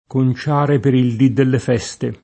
il d& ffut2ro del d& ppre@$nte pLu nnoL1So e tt$tro] (Leopardi); conciare per il dì delle feste [